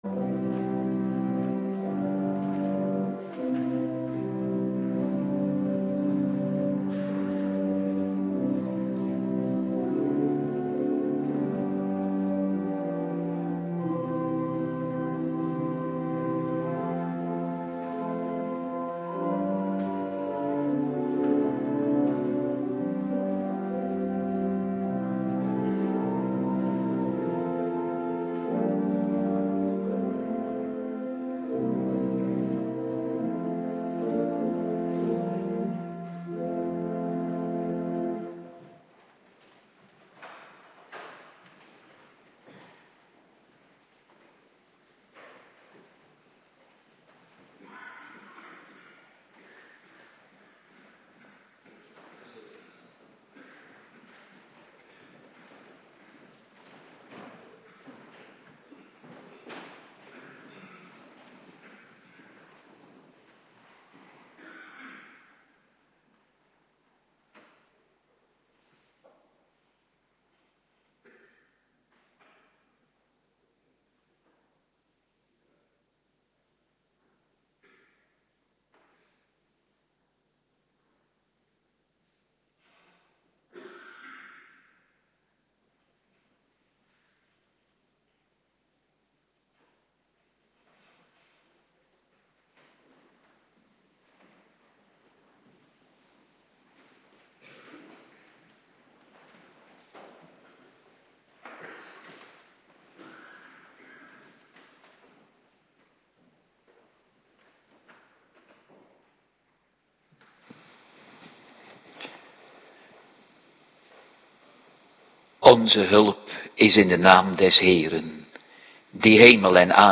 Woensdagavonddienst
Locatie: Hervormde Gemeente Waarder